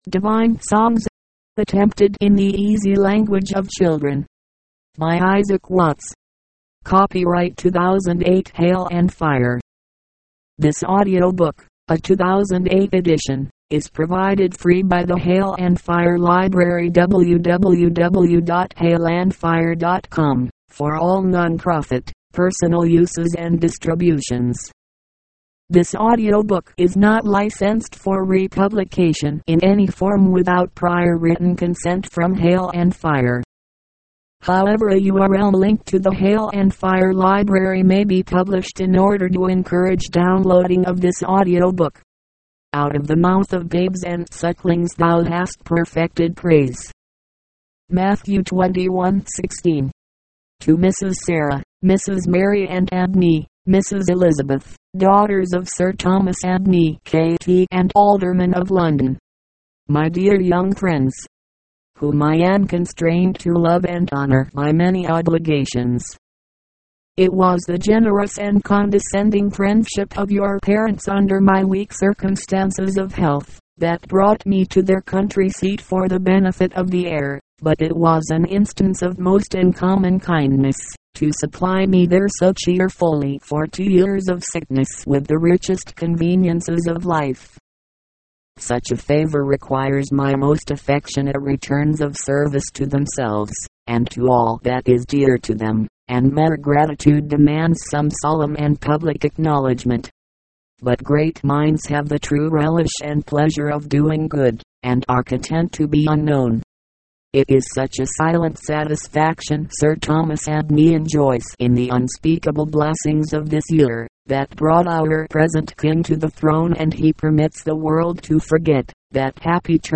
HAIL & FIRE - MP3 Audio Books: Isaac Watts: Divine Songs